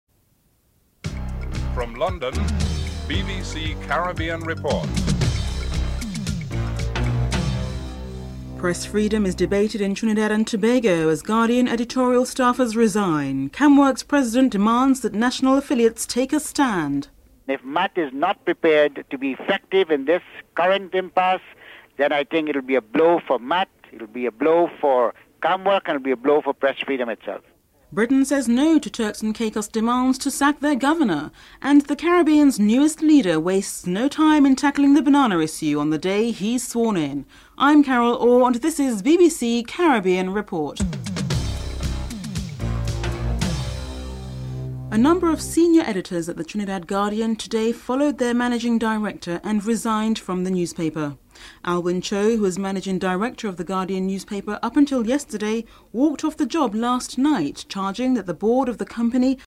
1. Headlines (00:00-00:43)
4. Britain says no to Turks and Caicos demand to sack their governor. Britain's Foreign and Commonwealth Minister Malcolm Rifkind is interviewed (05:38-07:10)
9. Britain supports the United States wish for democracy in Cuba but does not support the legislation tightening trade sanctions. Secretary of State for Foreign and Commonwealth Affairs Malcolm Rifkind is interviewed (13:00-14:42)